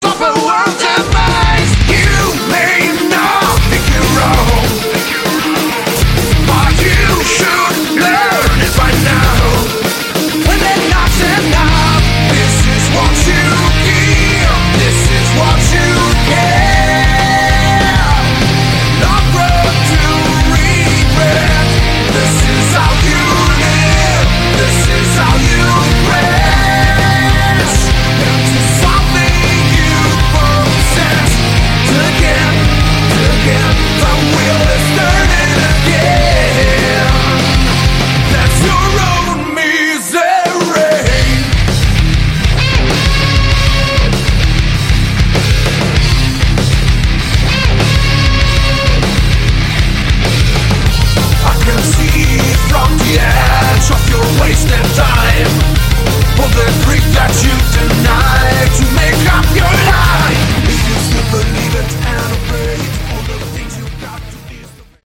Category: Hard Rock
vocals
guitars
bass
keyboards
drums